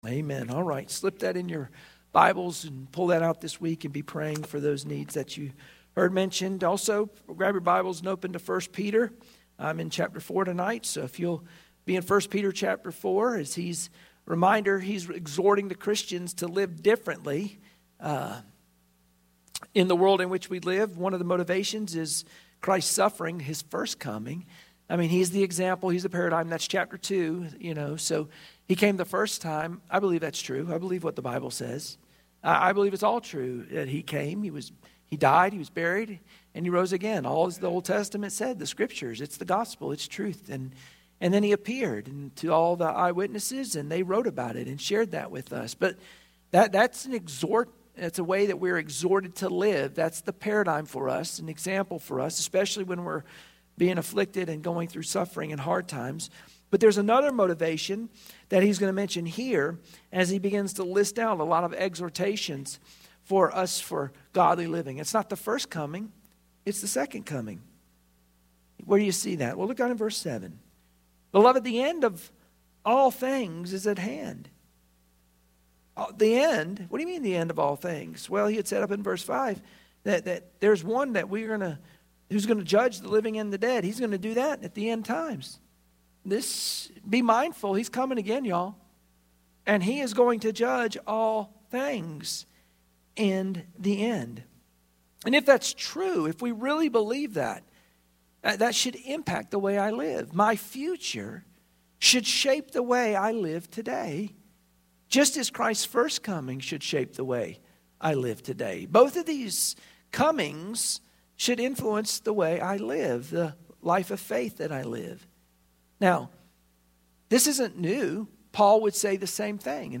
1 Peter 4:12-19 Service Type: Wednesday Prayer Meeting Share this